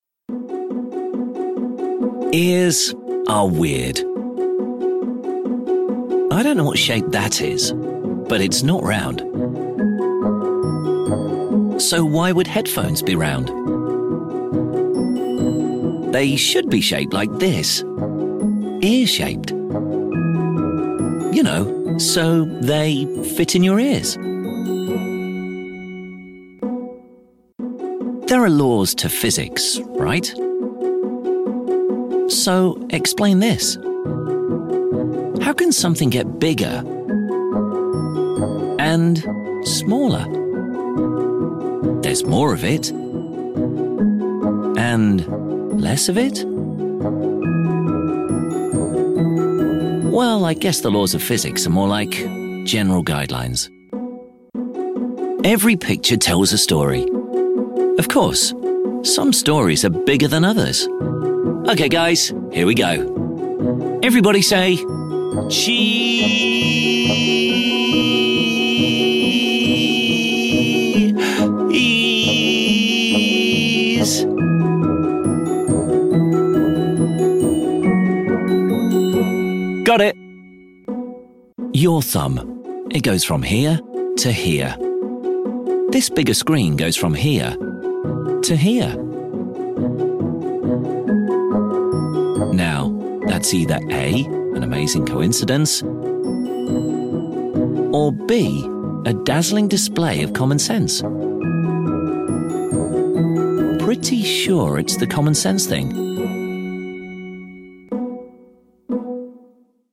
Apple Voice Style – British Male Voice | VoiceoverGuy
It’s understated, intelligent, and effortlessly clear.
iPhone TV adverts
The tone is simple, elegant, and precise. A British commercial voiceover style that lets the product do the talking.